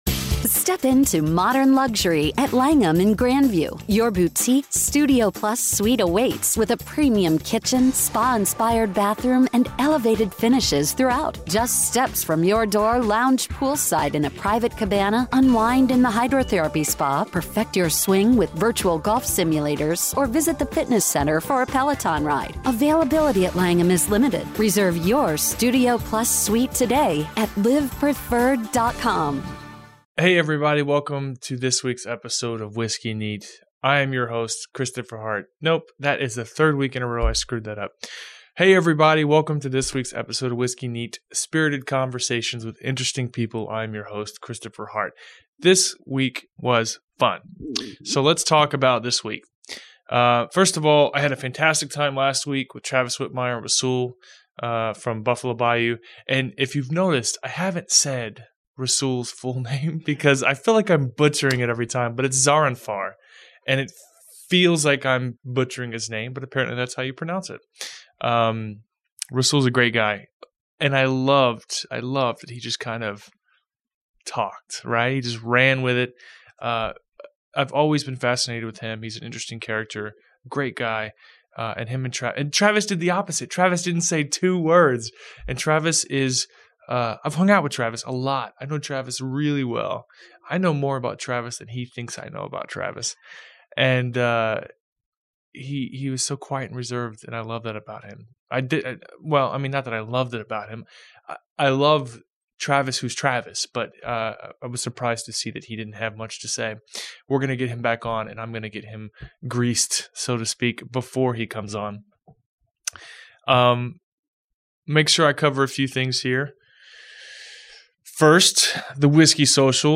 Whiskey Neat is a podcast and Radio show on iTunes and ESPN 97.5 FM in Houston.